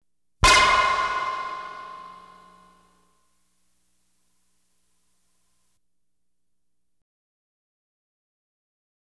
Light Beam Hit Sound Effect
Download a high-quality light beam hit sound effect.
light-beam-hit-2.wav